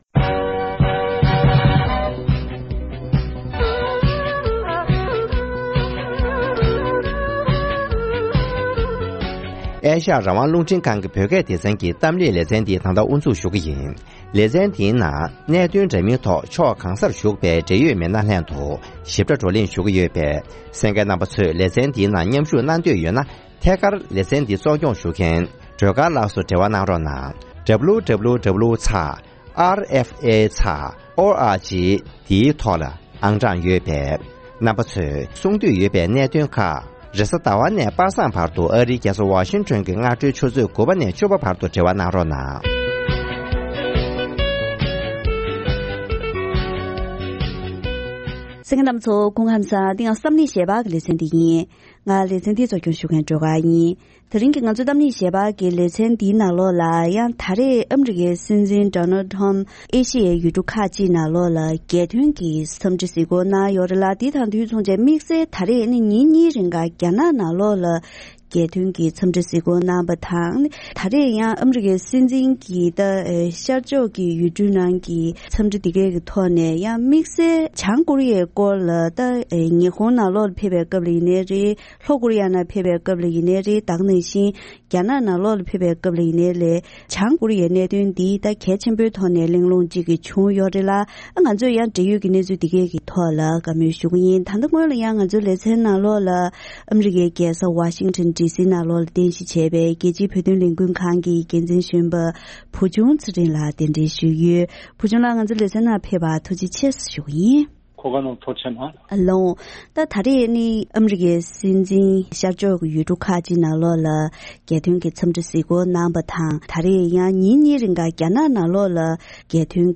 ཨ་རི་དང་རྒྱ་ནག་གི་འབྲེལ་བའི་ཐོག་ནས་བོད་དོན་དང་འགྲོ་བ་མིའི་ཐོབ་ཐང་ལ་སོགས་པའི་གནད་དོན་དང་། དེ་མིན་ལྷོ་བྱང་ཀོ་རི་ཡའི་གནད་དོན་སོགས་ཀྱི་ཐད་གླེང་མོལ།